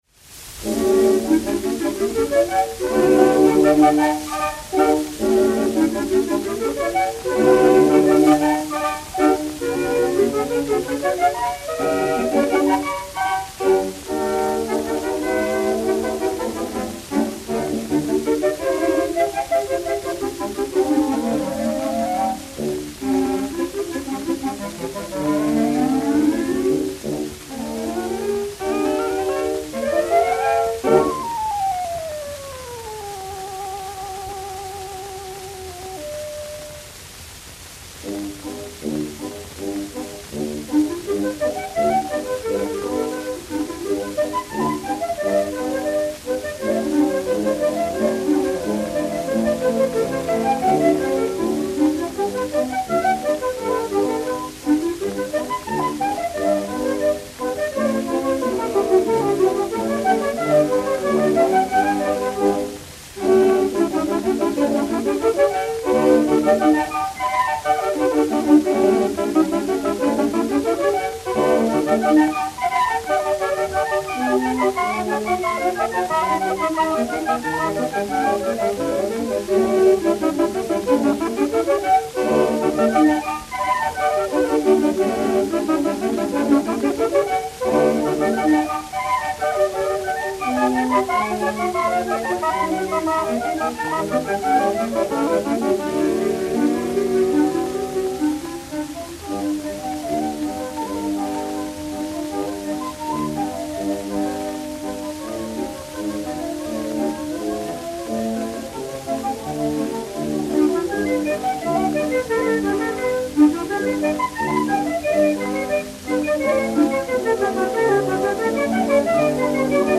Suite d'orchestre des Deux Pigeons
Musique de la Garde Républicaine